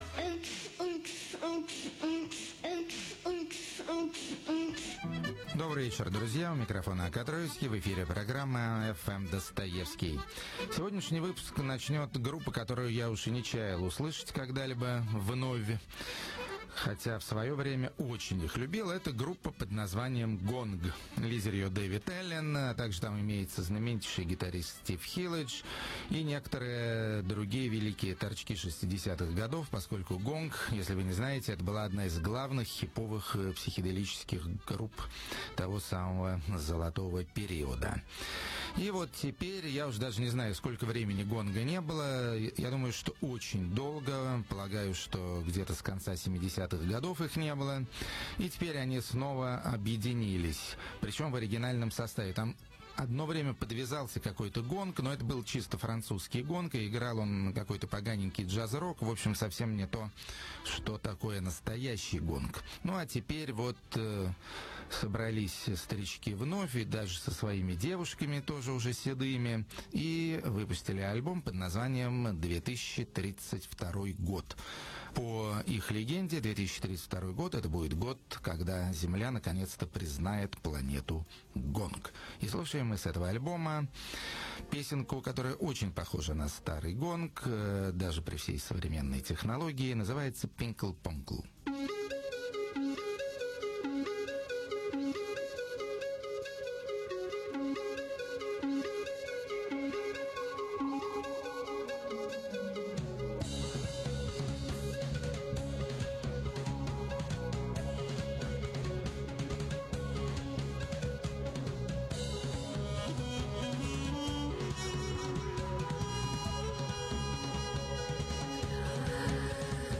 Качество ниже обычного, потому что записано из другого источника.